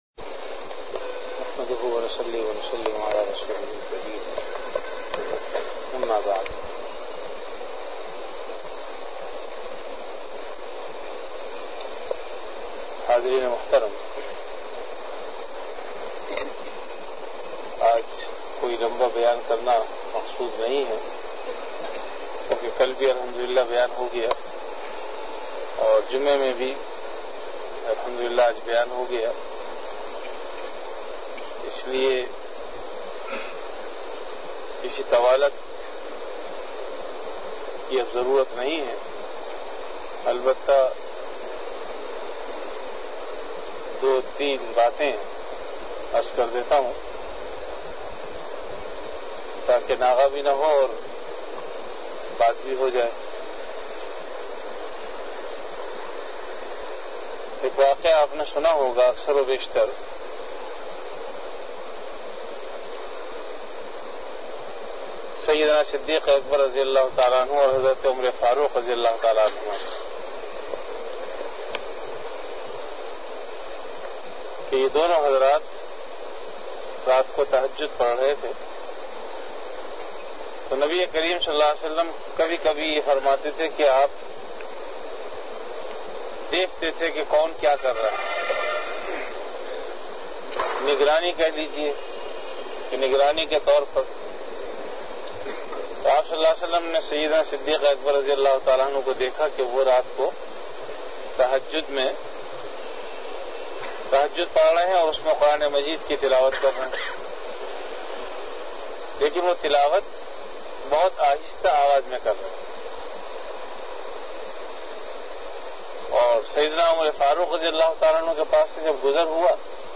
Bayanat · Jamia Masjid Bait-ul-Mukkaram, Karachi
Event / Time After Isha Prayer